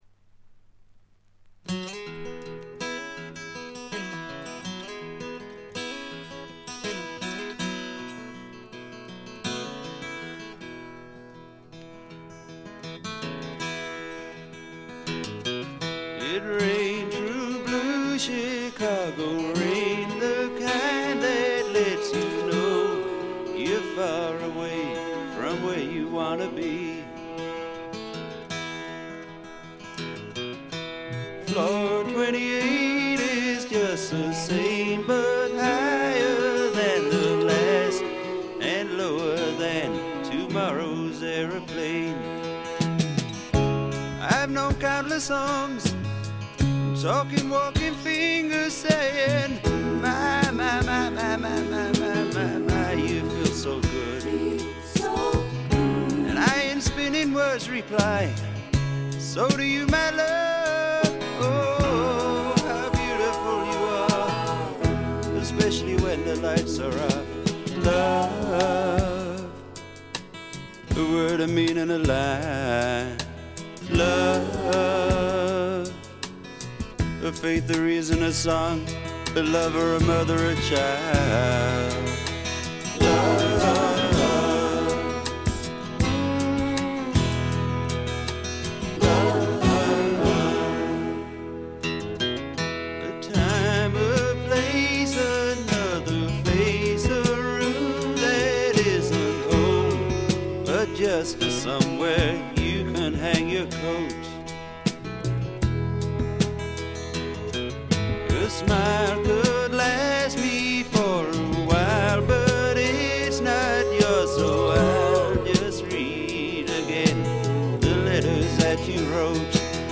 ほとんどノイズ感無し。
ずばり英国スワンプの名作です！
多少枯れぎみの哀愁を帯びた声で、ちょっと投げやりな歌い方もサウンドの雰囲気にマッチしています。
試聴曲は現品からの取り込み音源です。